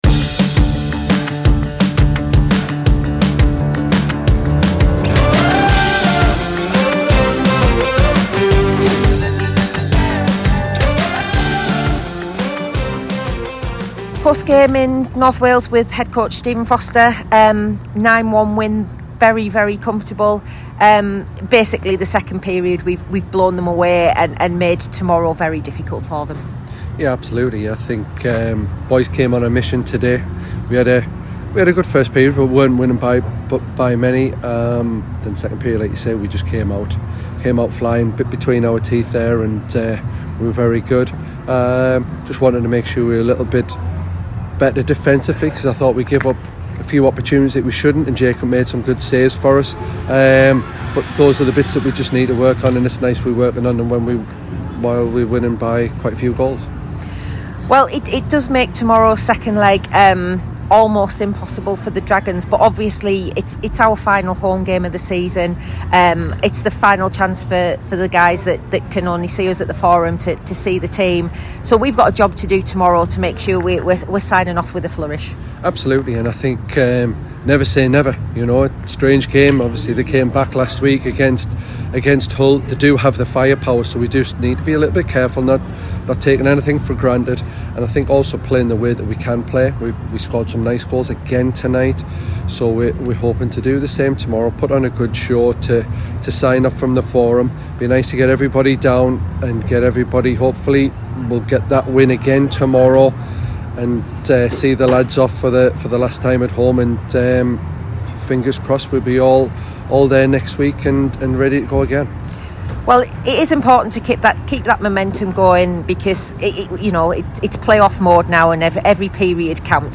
Post-Game